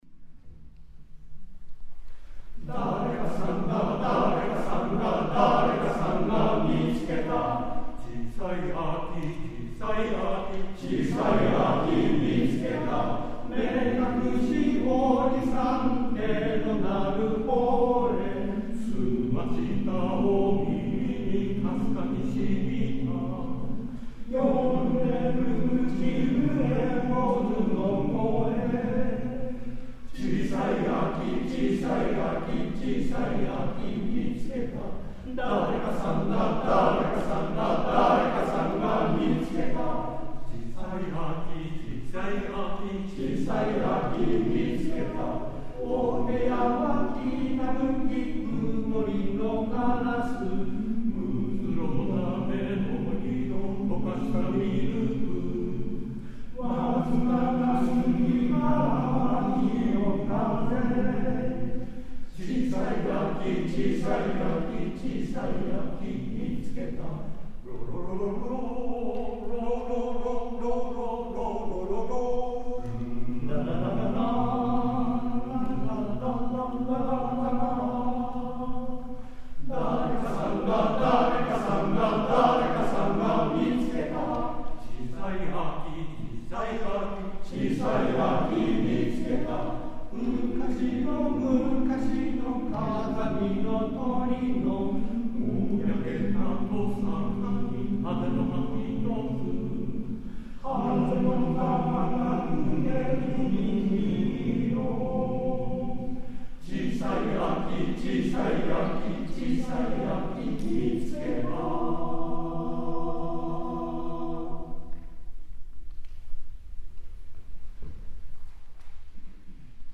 世田谷合唱祭
会場 世田谷区民会館